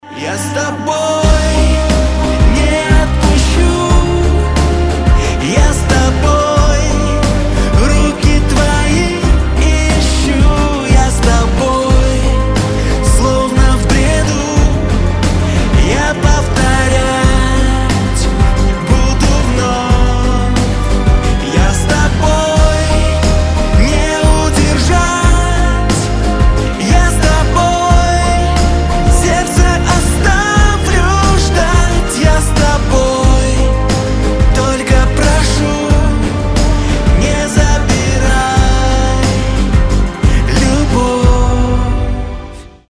• Качество: 256, Stereo
поп
мужской вокал